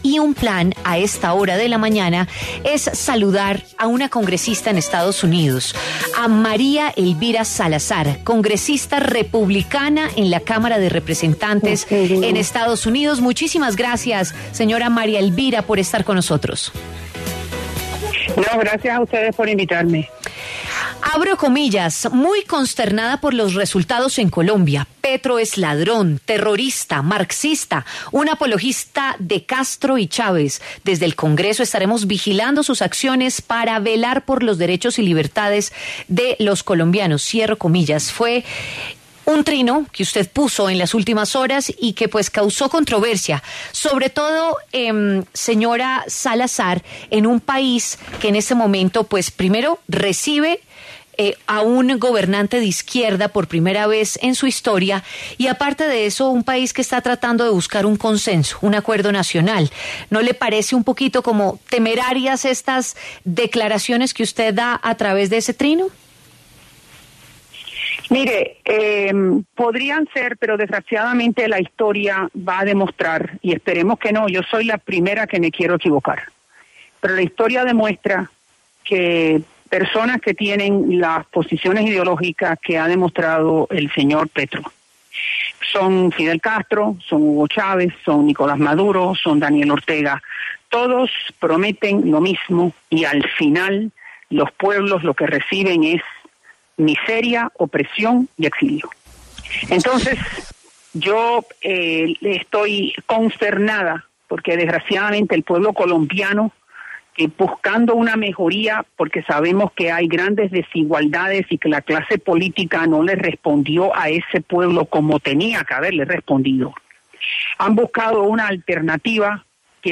María Elvira Salazar, republicana en la Cámara de Representantes en EE.UU., habló en La W sobre las elecciones presidenciales de Colombia. Aseguró que Gustavo Petro no es la solución a la problemática del país.